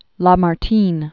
(lä-mär-tēn), Alphonse Marie Louis de Prat de 1790-1869.